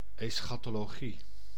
Ääntäminen
IPA: /ɛsˌxatoloˈɡi/